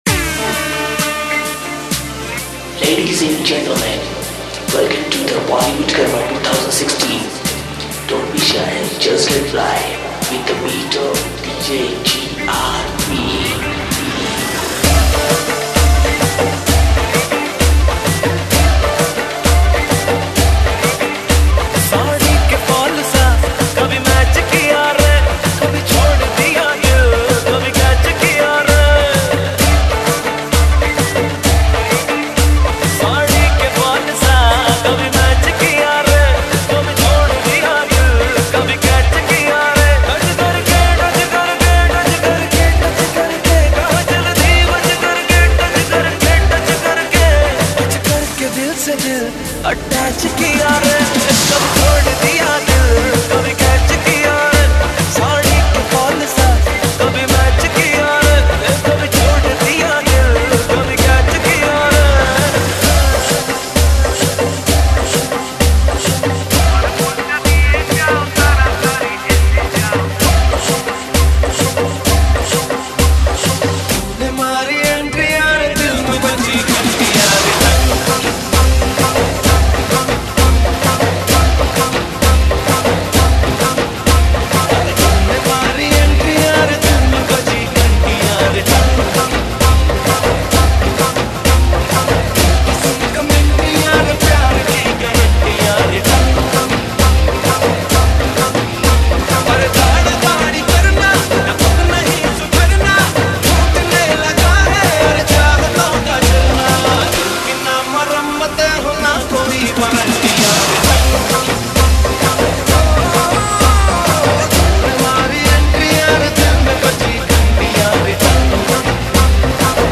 DJ Remix Mp3 Songs > Single Mixes